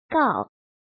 怎么读
gào